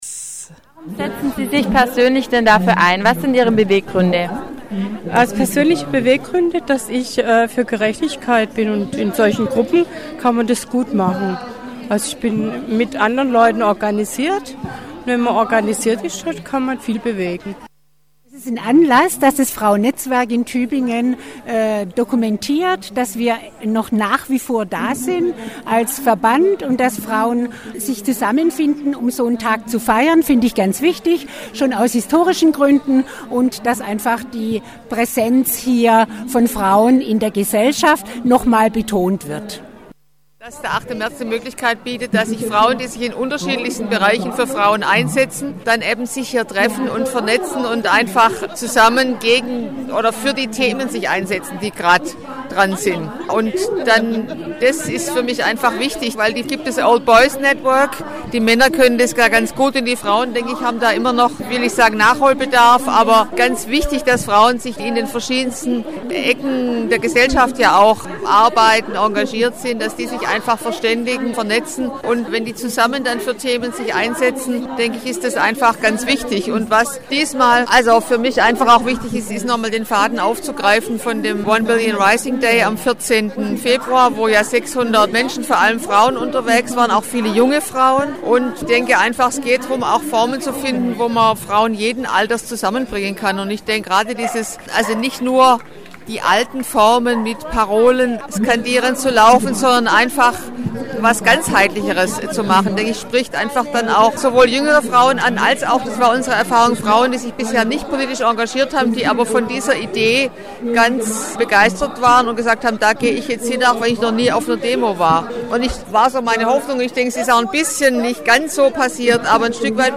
Wir waren mitten drin im Tübinger Holzmarktspektakel.
Mit Musik, Tanz und Reden  brachten sie ihre Anliegen zum Ausdruck.
Es herrschte ein buntes Treiben und eine nette   Atmosphäre.